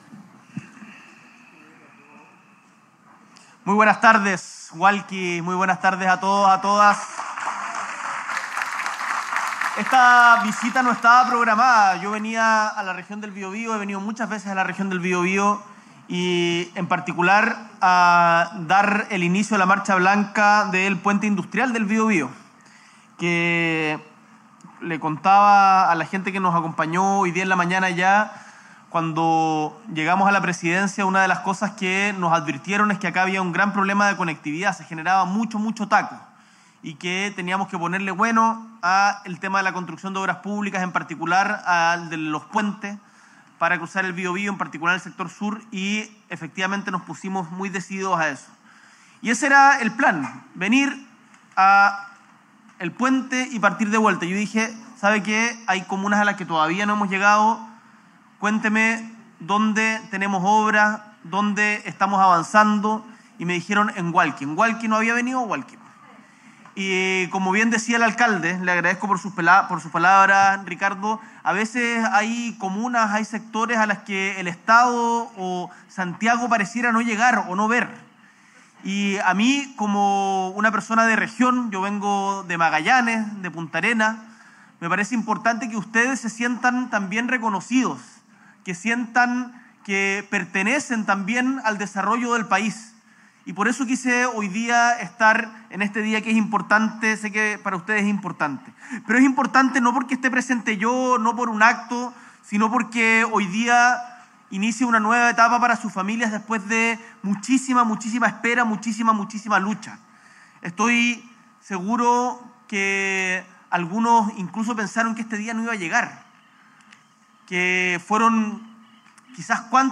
S.E. el Presidente de la República, Gabriel Boric Font, encabeza la ceremonia de entrega del proyecto habitacional Jardines de Hualqui, junto a la subsecretaria de Vivienda y Urbanismo, Gabriela Elgueta; el delegado presidencial regional del Biobío, Eduardo Pacheco; y el alcalde de Hualqui, Ricardo Fuentes.
Discurso